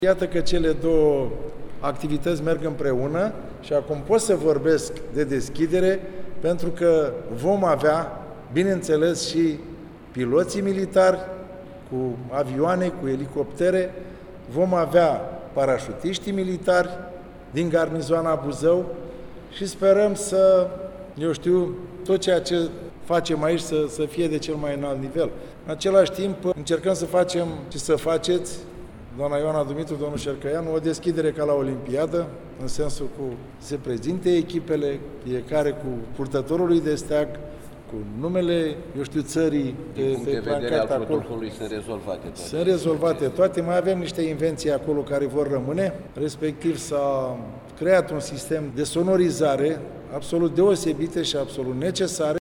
În cadrul unei conferințe de presă organizată la Primăria Buzău